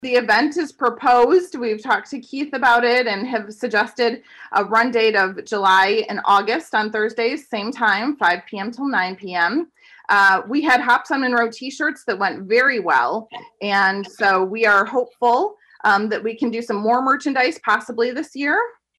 during Monday’s Coldwater City Council meeting